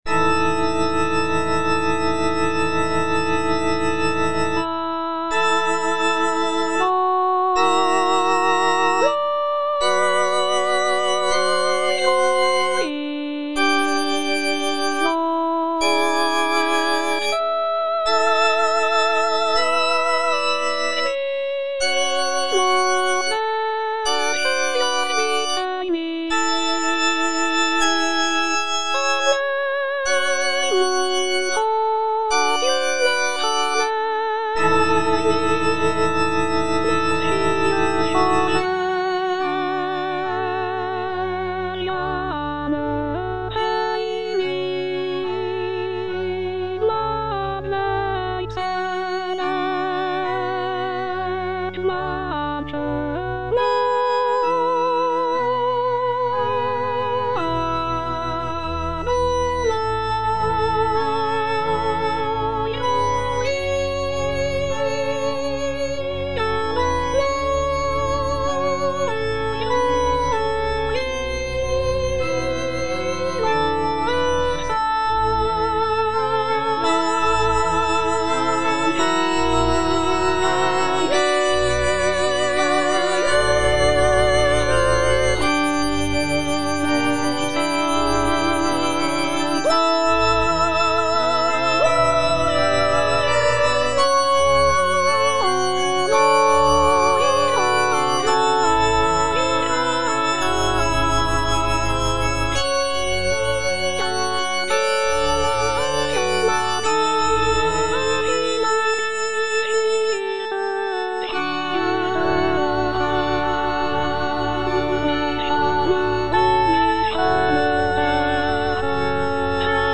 (tenor I) (Emphasised voice and other voices) Ads stop